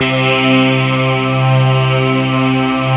Index of /90_sSampleCDs/Best Service Dream Experience/SYN-PAD